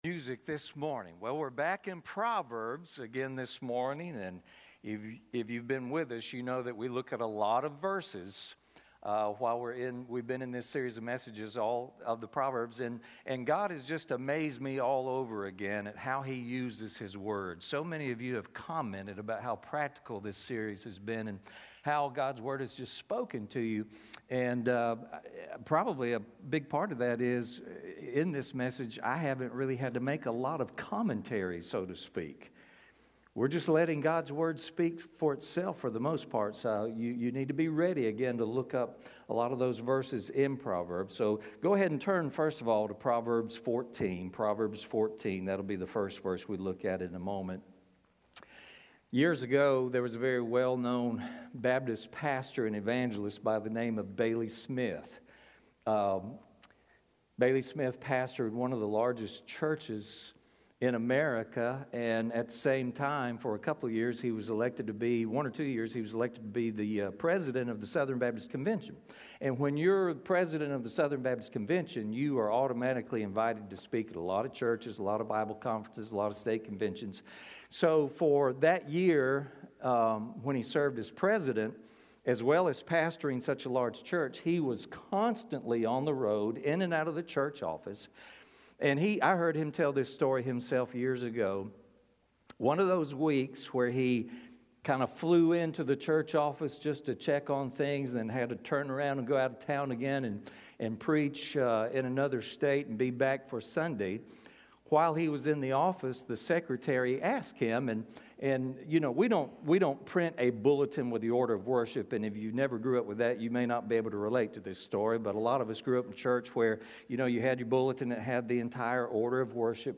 Sermons - The Fellowship